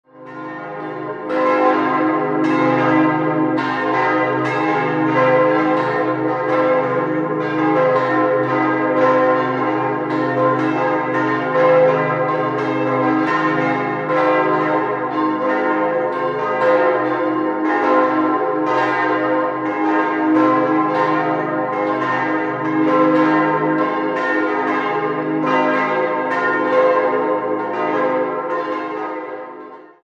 Dreifaltigkeitsglocke c' 2.008 kg 155 cm 1953 Georg Hofweber, Regensburg Muttergottesglocke es' 1.100 kg 128 cm 1953 Georg Hofweber, Regensburg Andreasglocke f' 805 kg 115 cm 1953 Georg Hofweber, Regensburg Josefsglocke as' 469 kg 96 cm 1953 Georg Hofweber, Regensburg Marienglocke c'' ??? kg 85 cm 1451 ubz., vermutlich Nürnberger Gießer